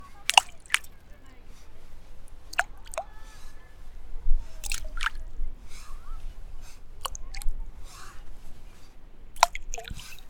물방울.mp3